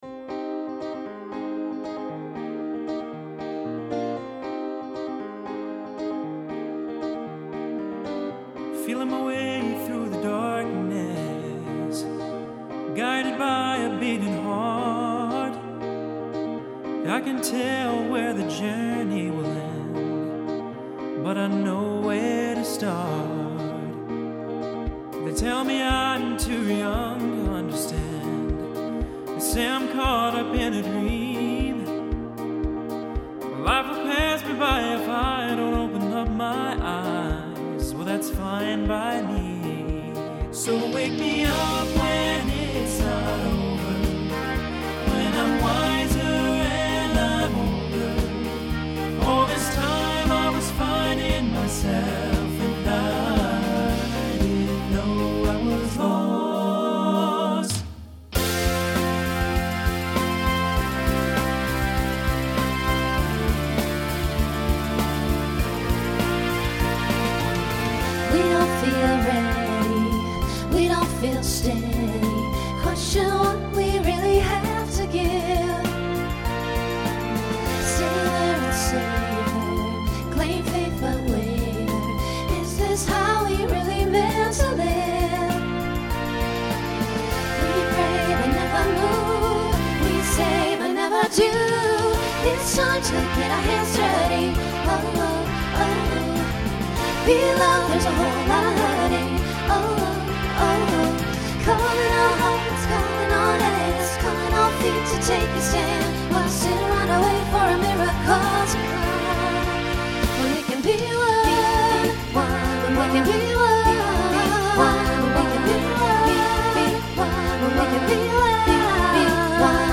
TTB/SSA
Voicing Mixed Instrumental combo Genre Pop/Dance